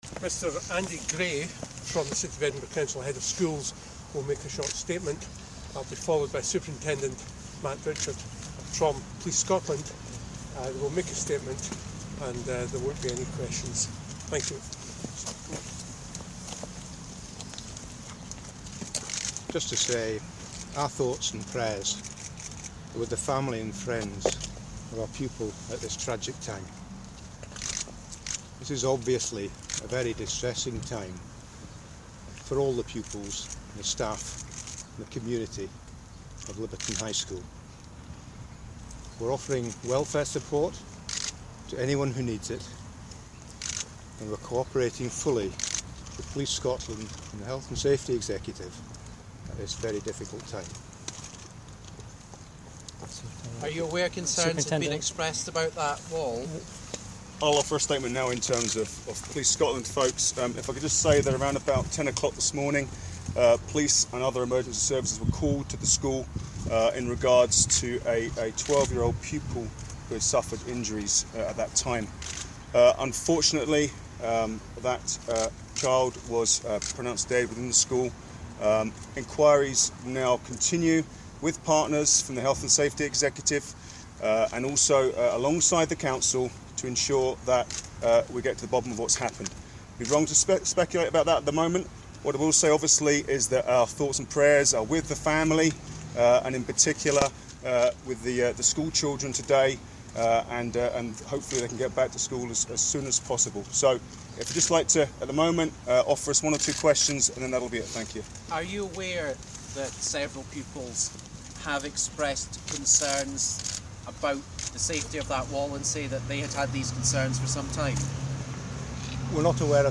Liberton High School: statement
make a statement outside Liberton High School